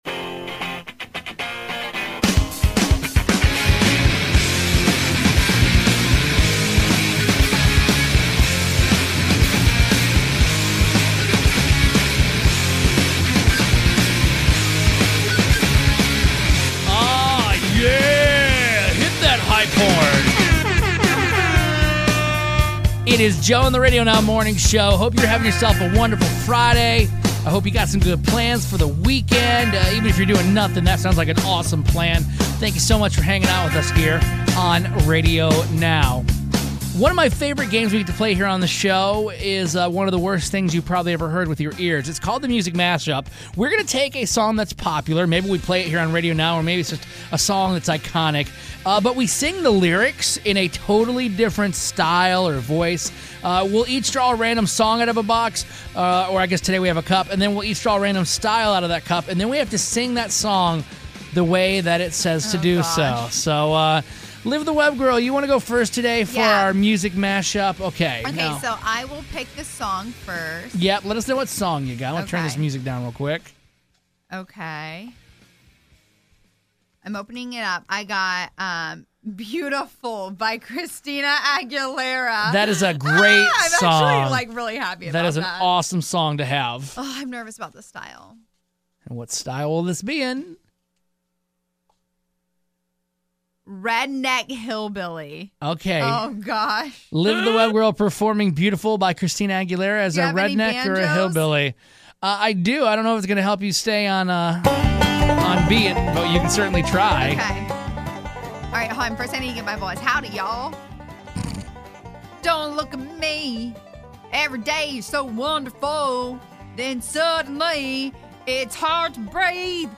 We each randomly pick a popular song & a random style to sing it in.